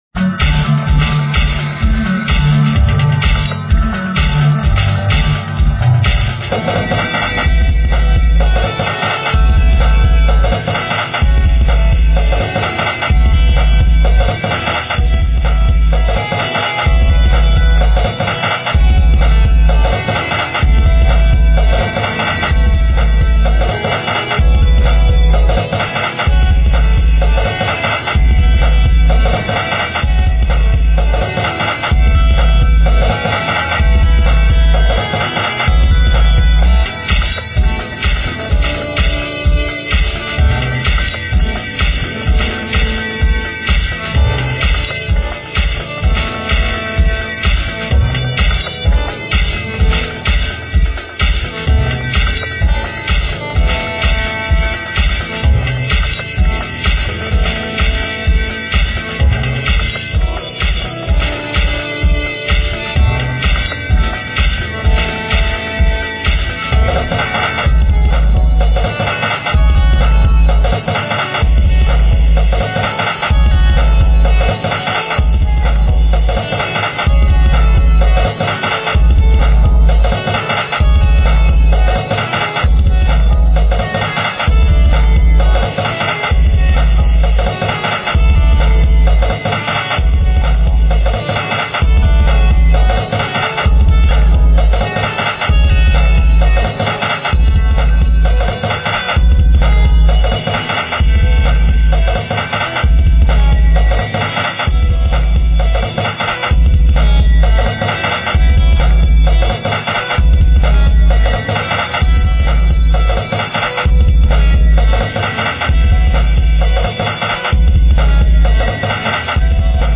Royalty Free Music for use in any type of
Upbeat, dominant and energetic synth music using
synth, bass and drums.